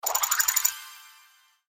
Coins_Top_Bar.mp3